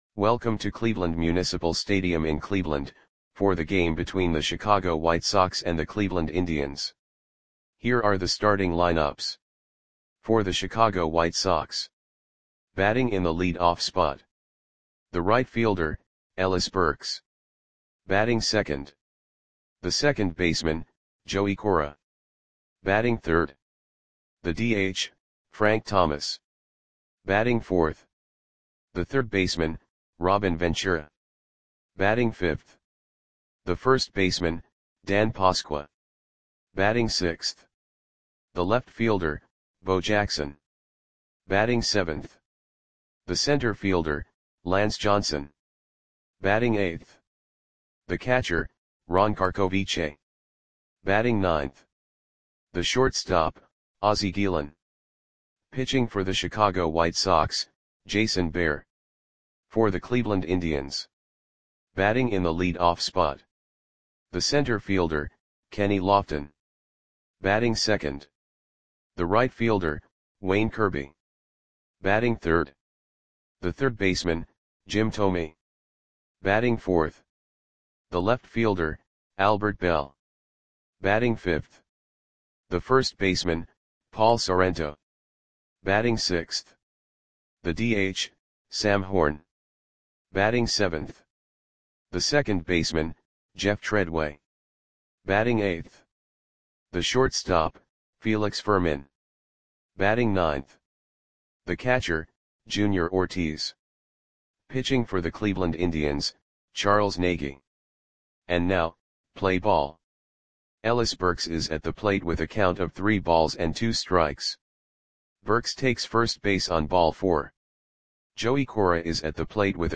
Audio Play-by-Play for Cleveland Indians on October 3, 1993
Click the button below to listen to the audio play-by-play.